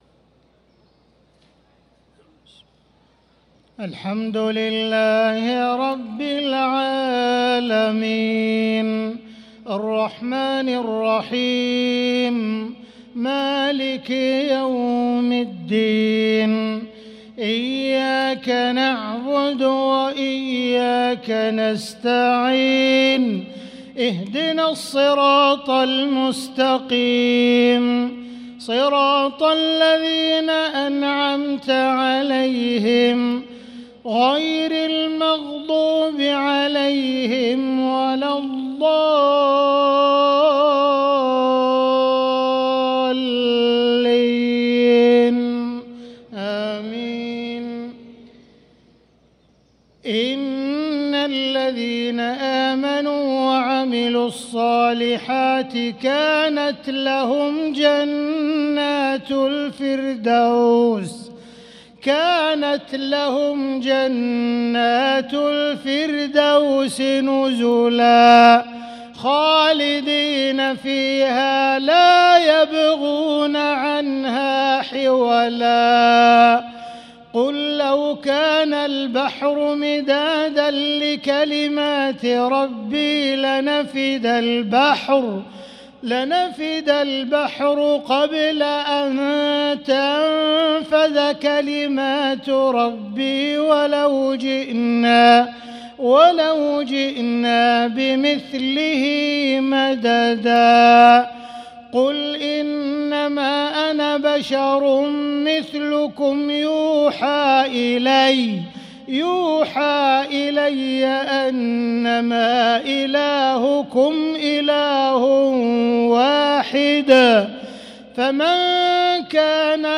صلاة المغرب للقارئ عبدالرحمن السديس 12 رمضان 1445 هـ
تِلَاوَات الْحَرَمَيْن .